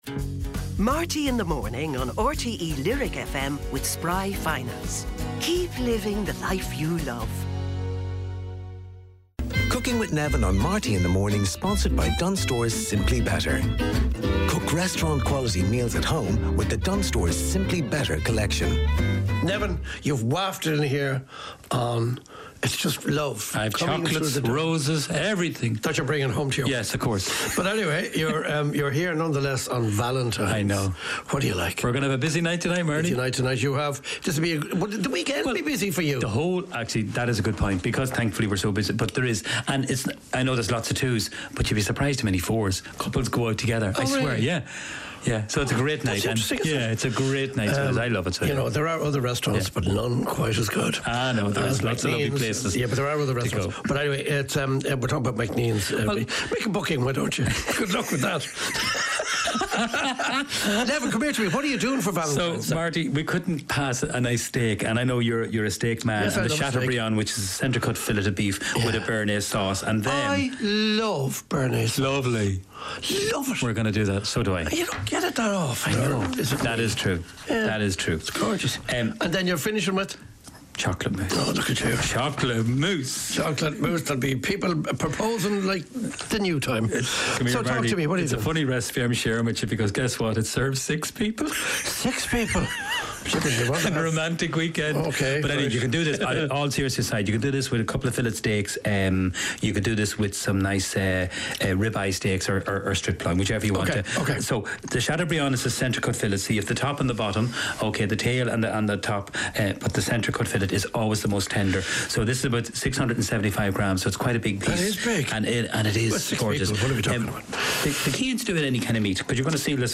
Highlights and special guest interviews and performances from Marty Whelan's breakfast show on RTÉ Lyric FM. Also includes recipes from the wonderful kitchen of Neven Maguire.